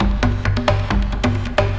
Index of /90_sSampleCDs/E-MU Formula 4000 Series Vol. 2 – Techno Trance/Default Folder/Tribal Loops X